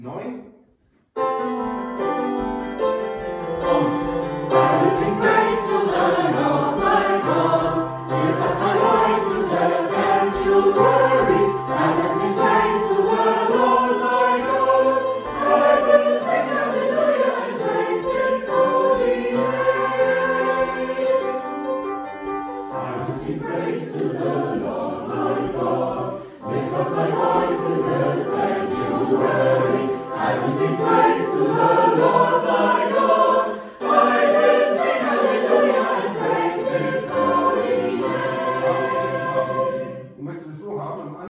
Herzlich Willkommen beim Gospelchor
Die Lieder wurden während unserer Probe mit einem Handy aufgenommen und haben keine Studioqualität.